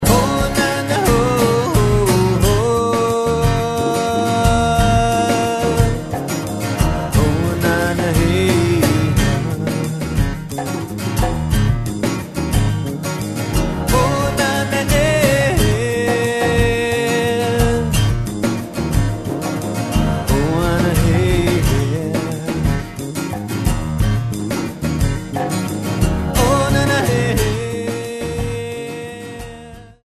quick changing meters